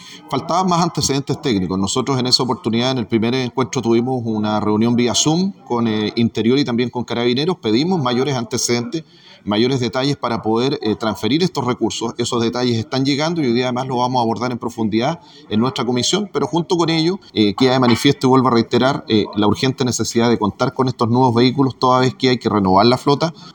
Según explicó el consejero, Jaime Vásquez, faltaban antecedentes.